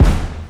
Stadium Kick.wav